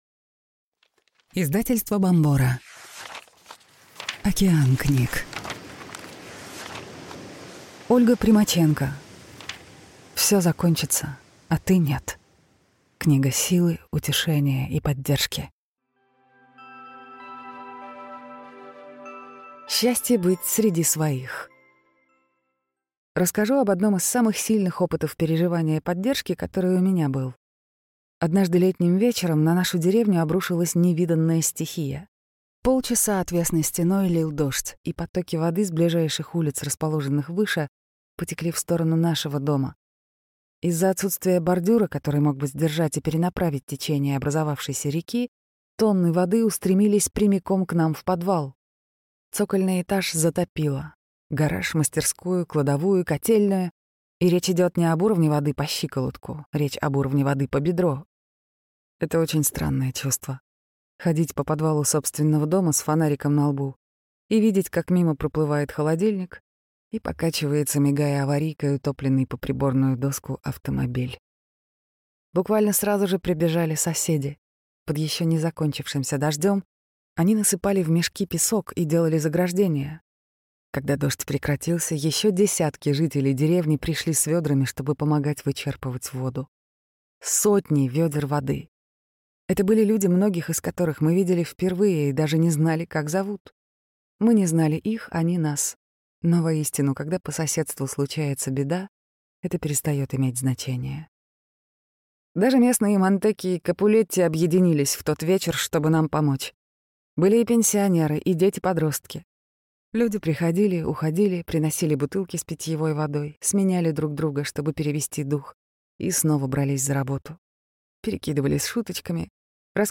Аудиокнига Всё закончится, а ты нет. Книга силы, утешения и поддержки | Библиотека аудиокниг